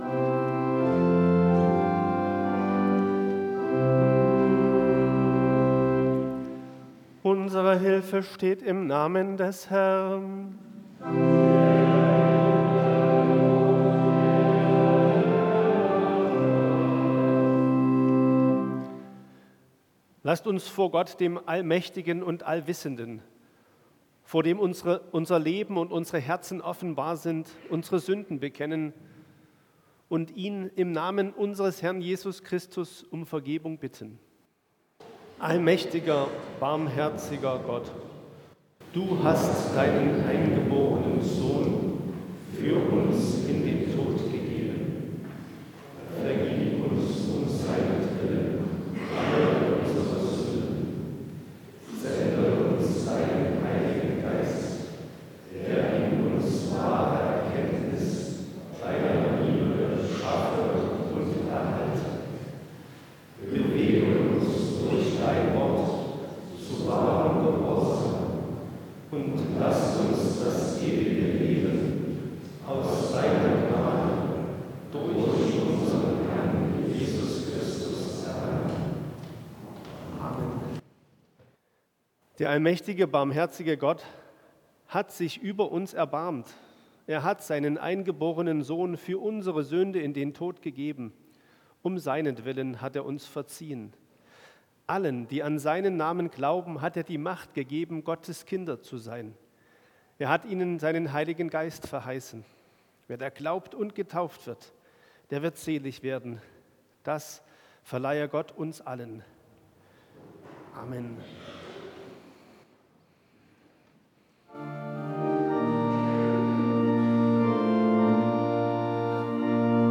Eingangsliturgie Ev.-Luth.
Audiomitschnitt unseres Gottesdienstes vom 4.Advent 2025.